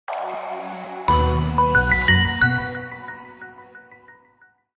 PowerOn.ogg